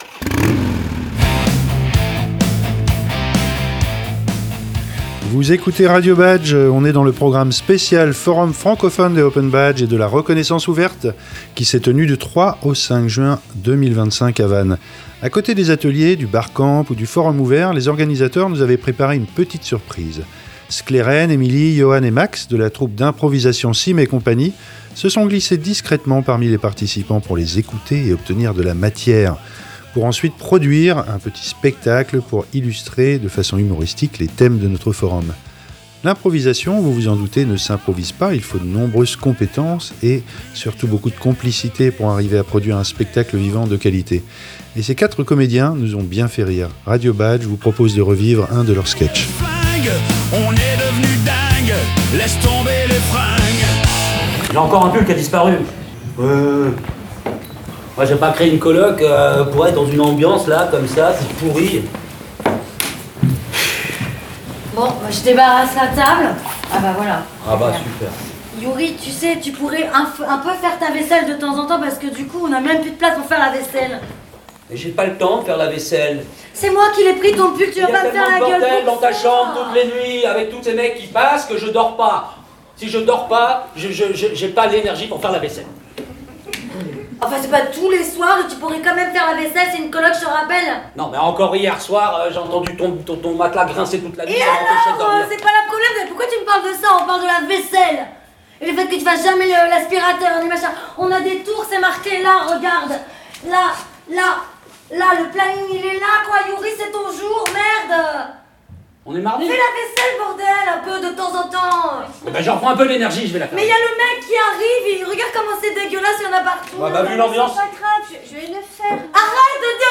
Et ces 4 comédiens nous ont bien fait rire. Radio Badges vous propose de revivre un de leurs sketchs.